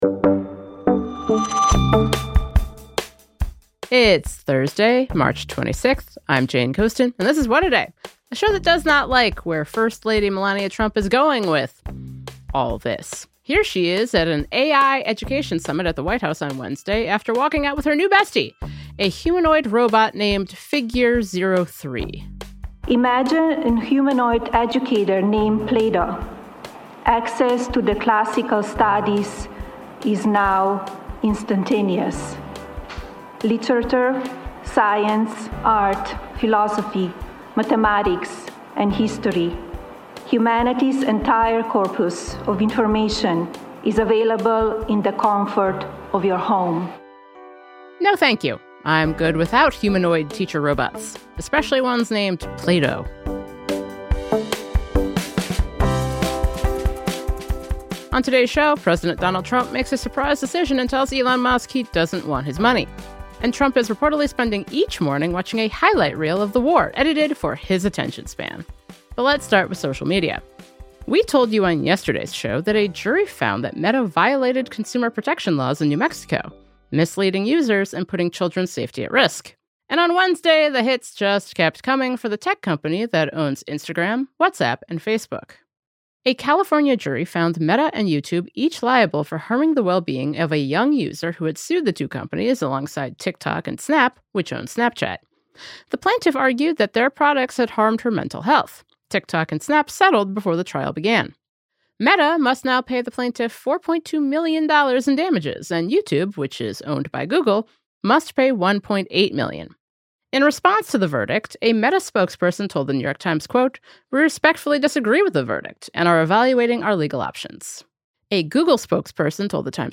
Jonathan Haidt, a social psychologist at New York University’s Stern School of Business and author of "The Anxious Generation," discusses what stronger regulations on young people could mean for all social media users.